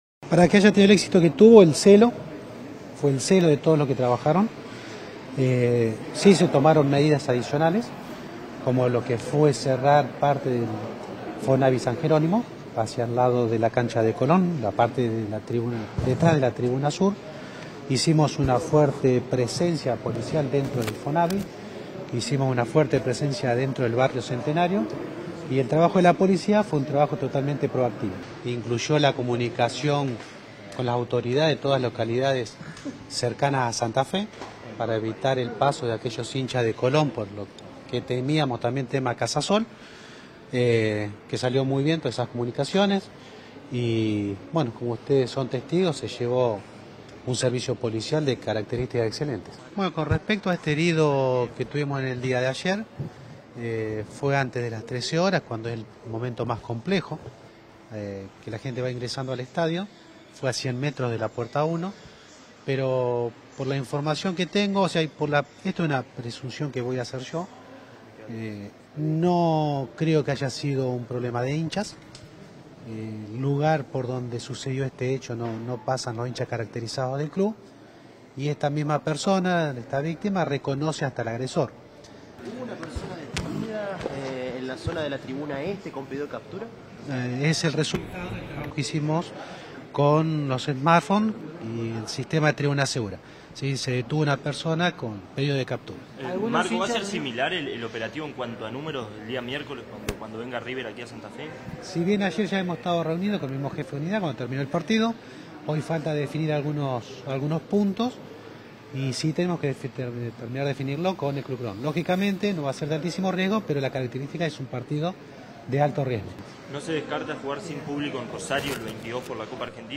Declaraciones de Romero.